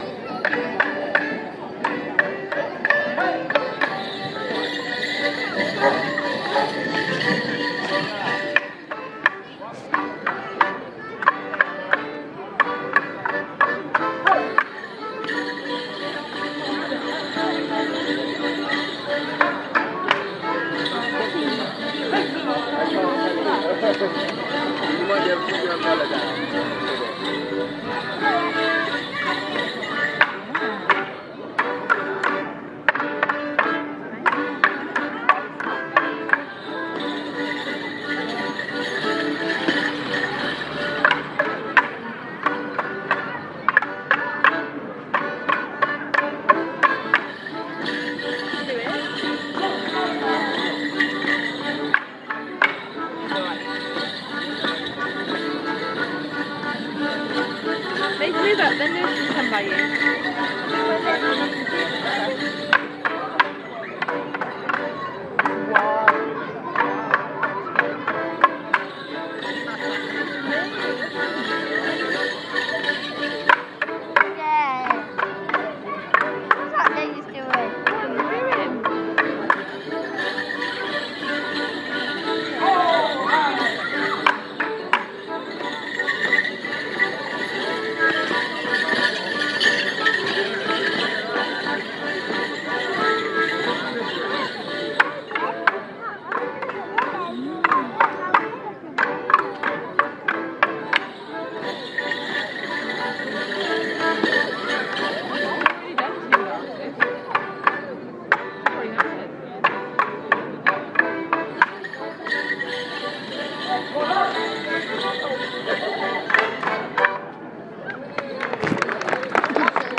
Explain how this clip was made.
More morris in the square